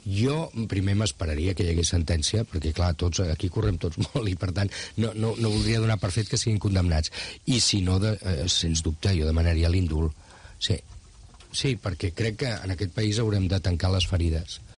El candidato a la Generalidad del PSC, Miquel Iceta, ha asegurado este miércoles durante una entrevista en Rac1 que es partidario de pedir el indulto para los exconsejeros del Govern y para los líderes de la ANC y Òmnium, Jordi Sànchez y Jordi Cuixart, si son condenados por los tribunales por saltarse la ley en su intento de llevar a Cataluña hasta la independencia.